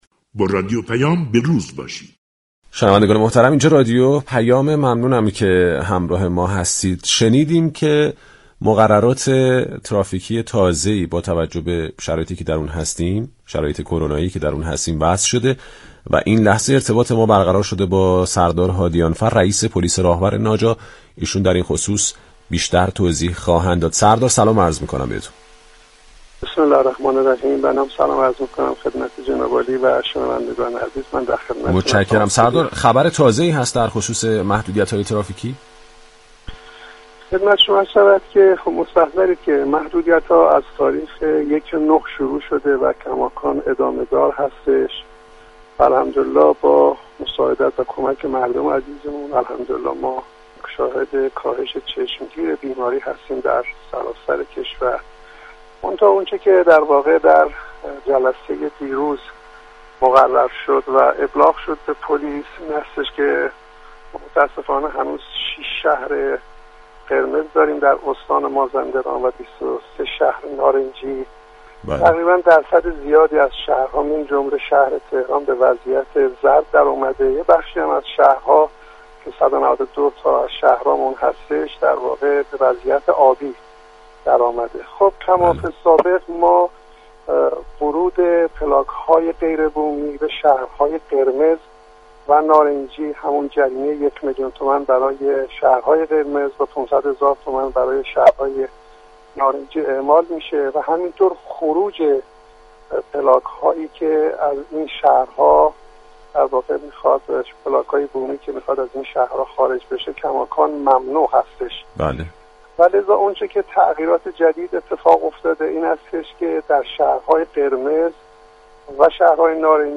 سردار هادیانفر رئیس پلیس راهور ناجا در گفتگو اختصاصی با رادیو پیام